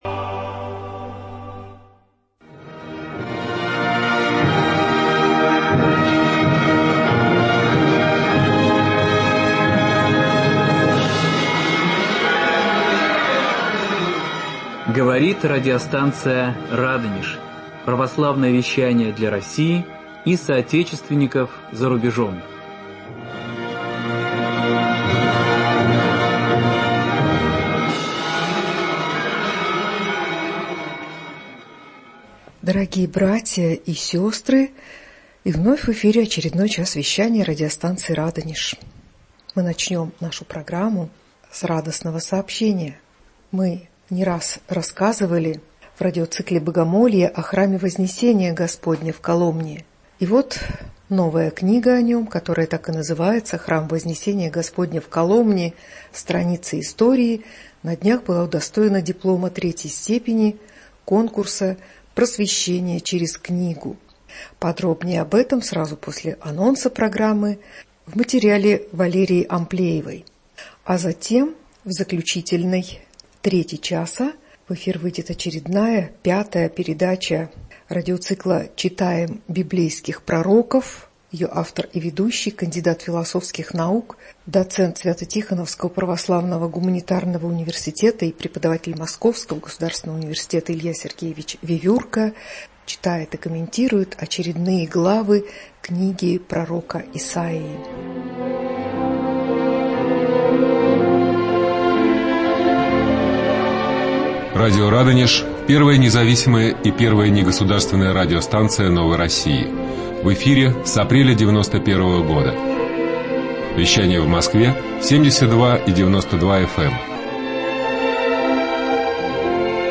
Храм Вознесения Господня в Коломне: страницы истории - издание-лауреат конкурса "Просвещение через книгу". Беседа